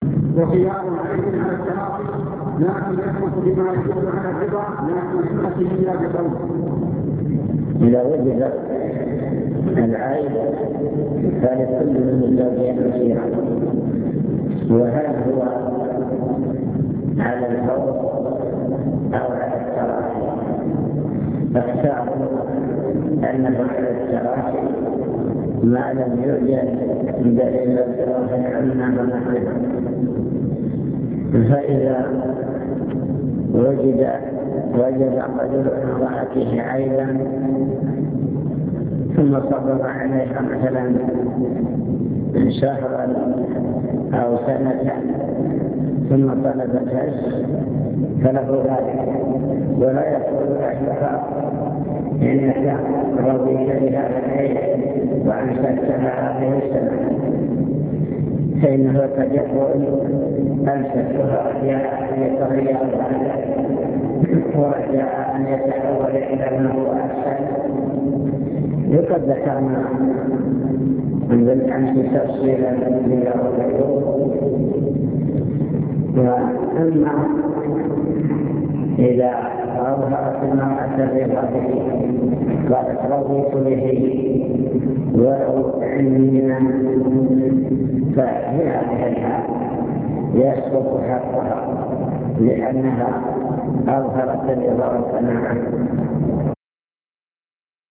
المكتبة الصوتية  تسجيلات - محاضرات ودروس  محاضرات في الزواج